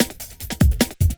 41 LOOPSD1-L.wav